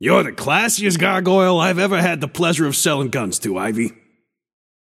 Shopkeeper voice line - You’re the classiest gargoyle I’ve ever had the pleasure of sellin‘ guns to, Ivy.